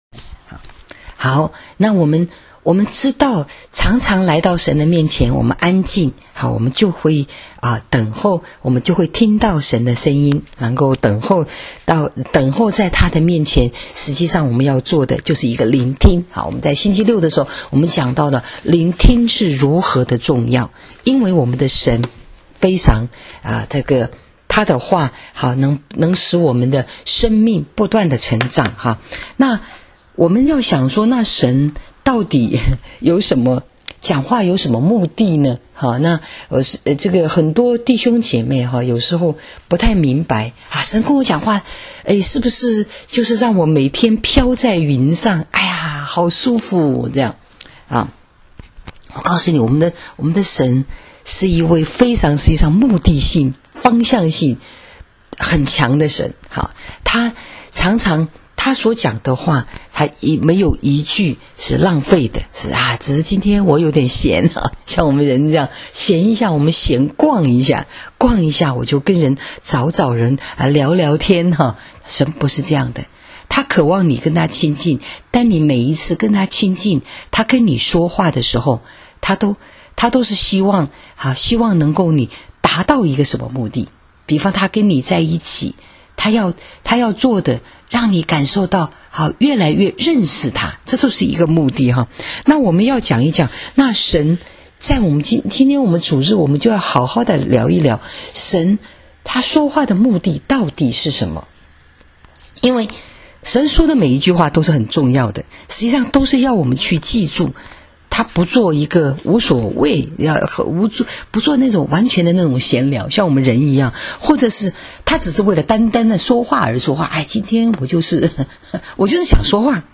【主日信息】神说话的目的 （8-18-19）